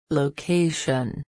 (ˈlaɪˌbrɛri)   biblioteca libraria bookstore